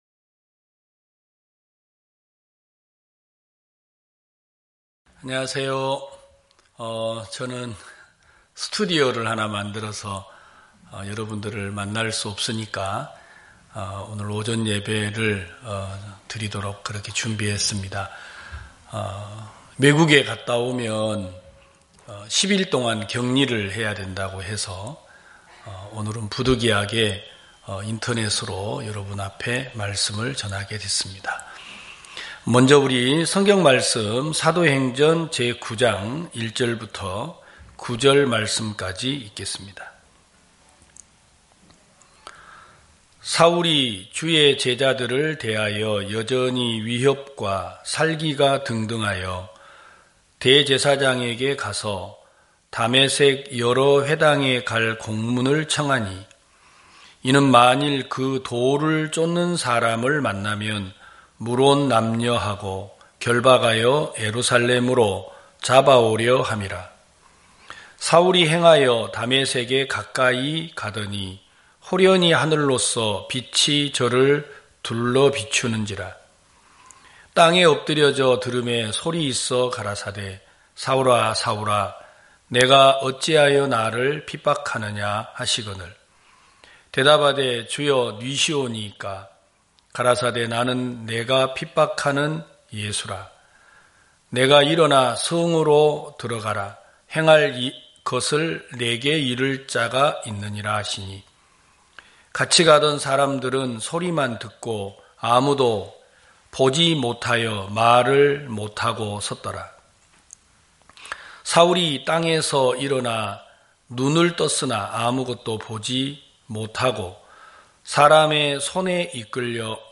2022년 01월 02일 기쁜소식부산대연교회 주일오전예배
성도들이 모두 교회에 모여 말씀을 듣는 주일 예배의 설교는, 한 주간 우리 마음을 채웠던 생각을 내려두고 하나님의 말씀으로 가득 채우는 시간입니다.